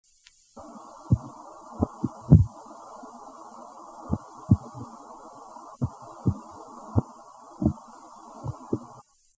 Sounds Made by Lutjanus synagris
Sound produced yes, active sound production
Type of sound produced knocks
Sound production organ swim bladder
Behavioural context when alarmed
Remark recorded with bandpass filter: 30 - 1200 Hz, recording amplified by 6 dB, noise reduction filter applied